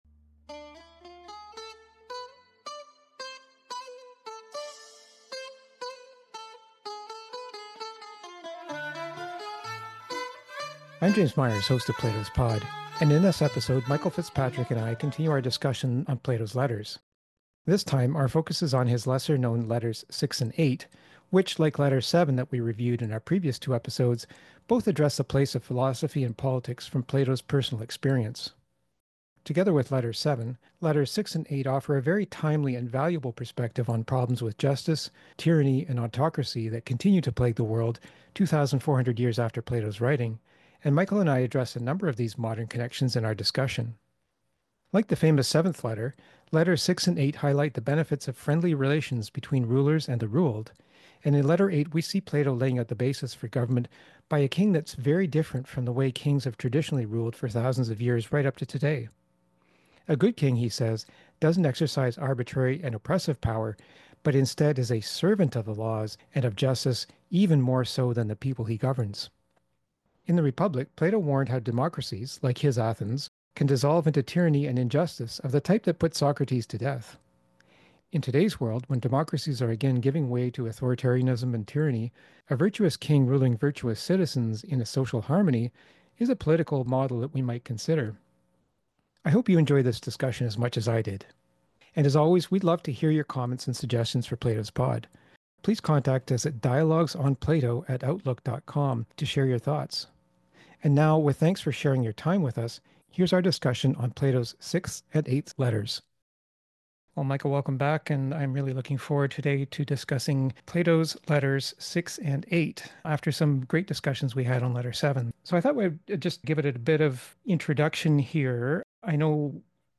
A spirited discussion ensued when members of the Toronto, Calgary, and Chicago Philosophy Meetup groups convened on April 28, 2024.